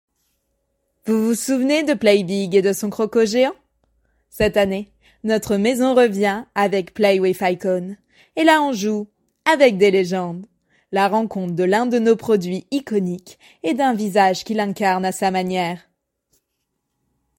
Essais voix
5 - 37 ans - Mezzo-soprano